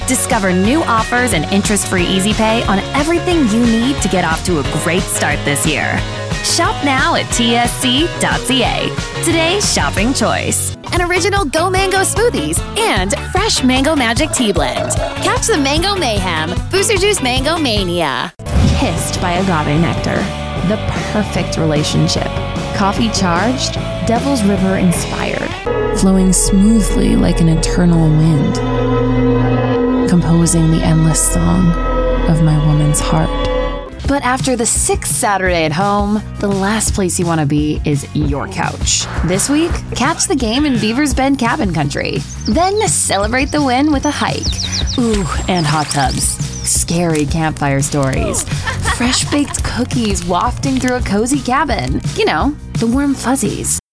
Young yet gritty voice, specializing in natural, laid back delivery!
Q2 2024 Commercial Demo